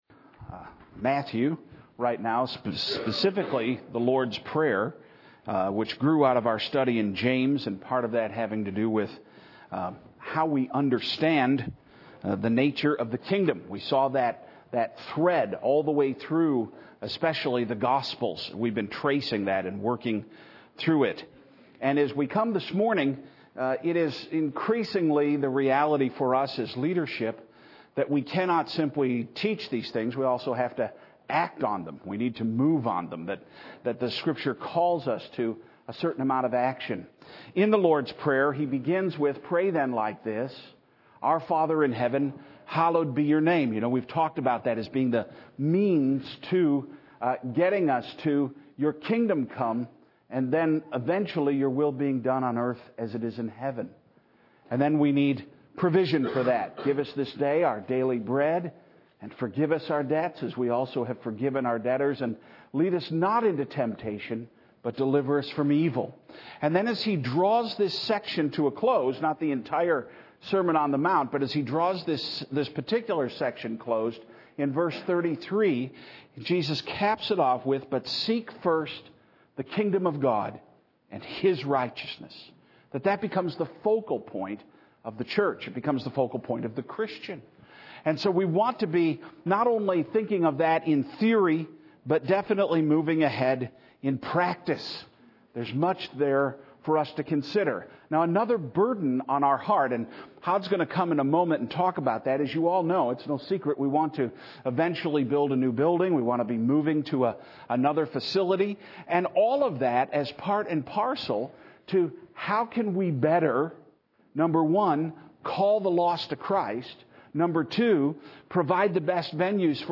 Budget Communications Meeting